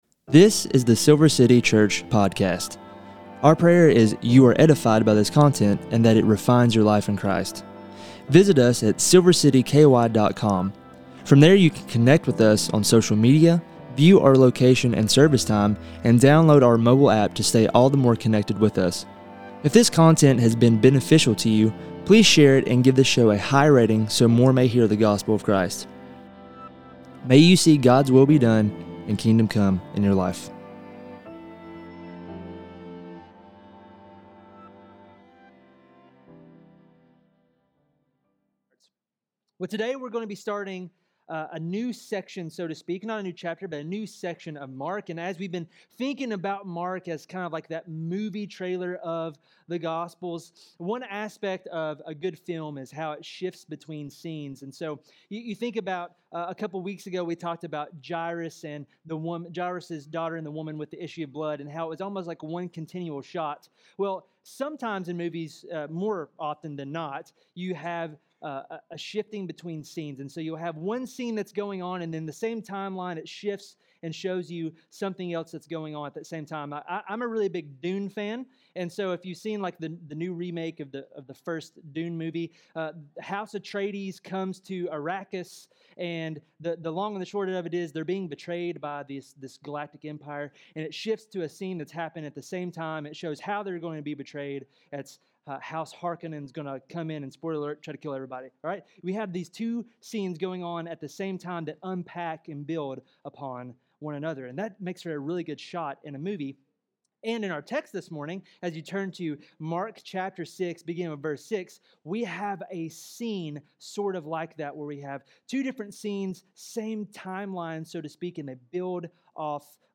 Sermons | Silver City Church